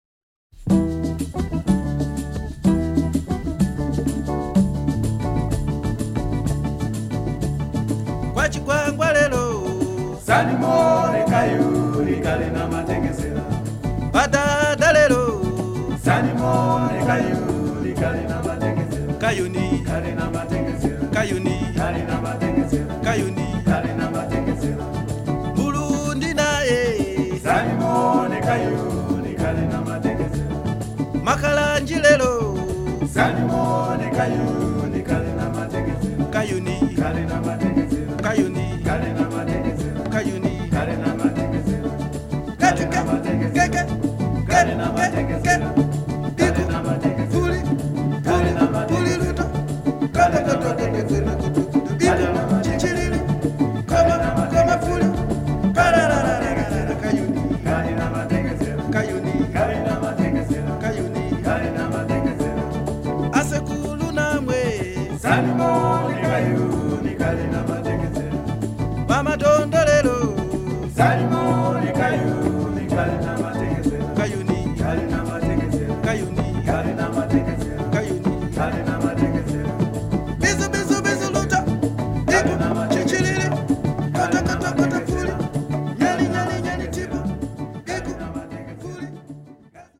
Beautiful Zambian Jazz/Rock LP.